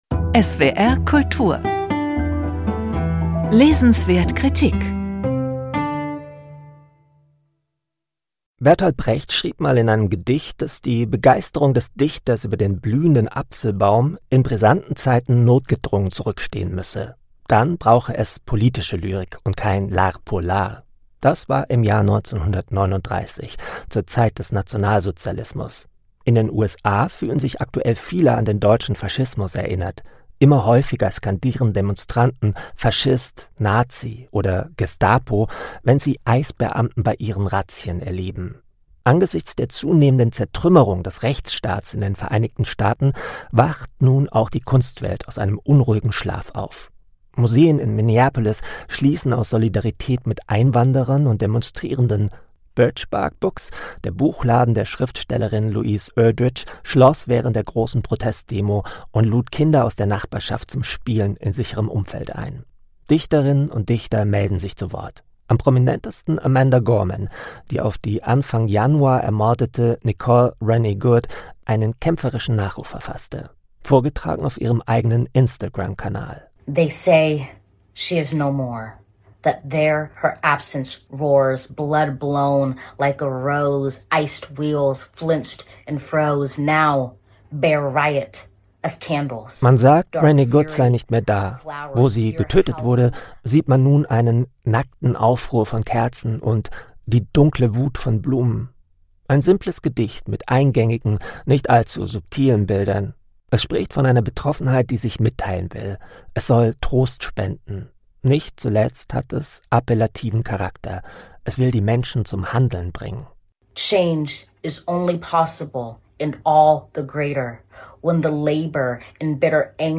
Reportage von